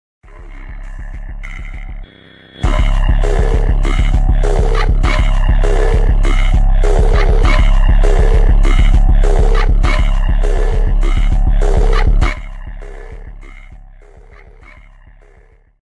Instrumental
extended/looped and edited by me